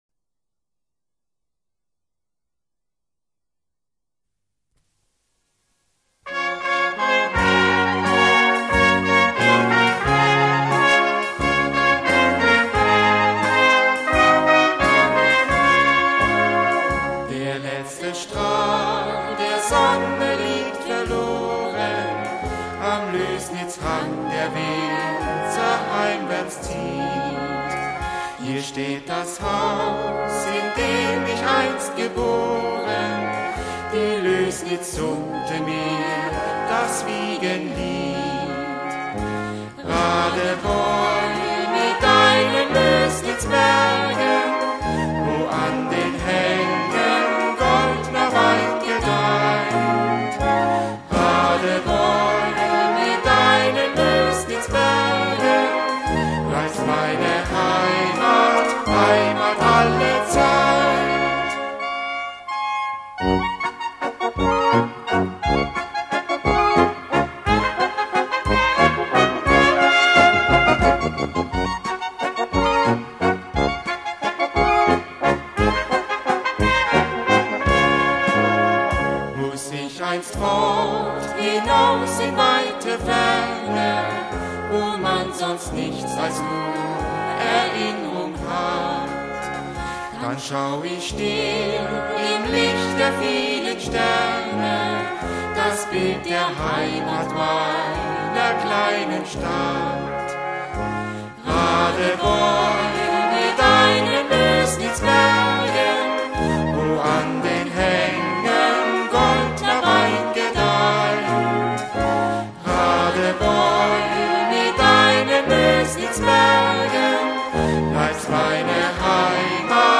Volkslied mit Gesang